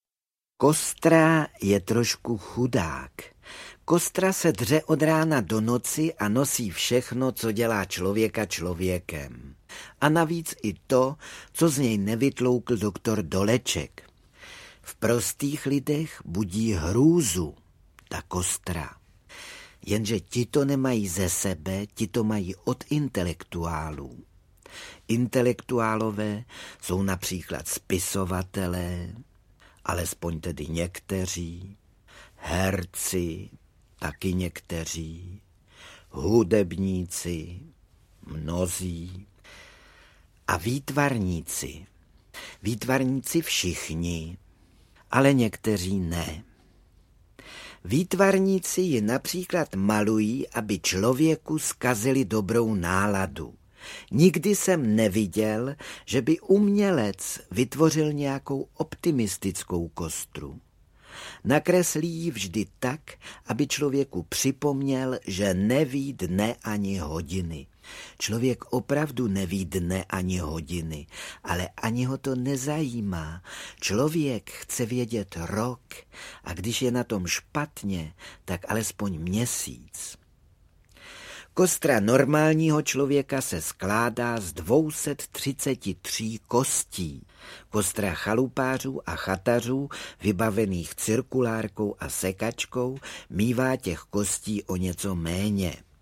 Audio kniha
Ukázka z knihy
• InterpretFrantišek Nepil